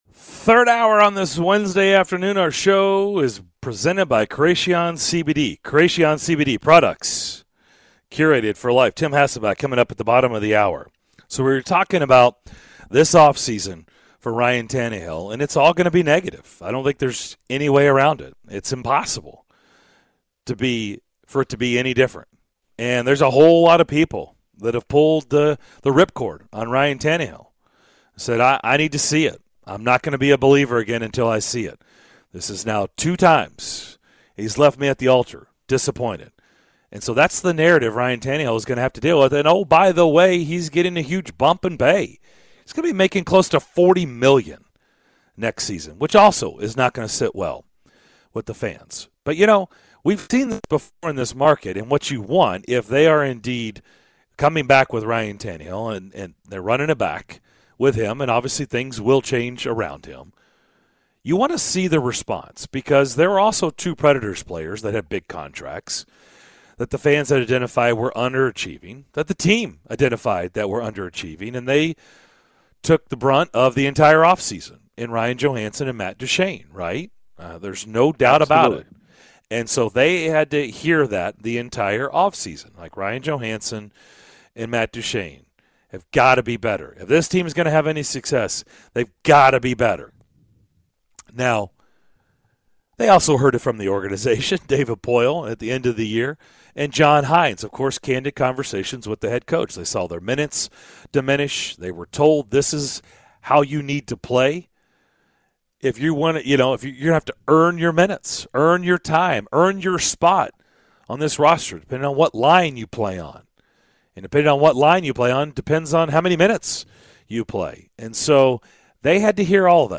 In the third hour of Wednesday's show: the guys evaluate the performance of Ryan Tannehill this season as a whole, talk with ESPN's Tim Hasselbeck and more!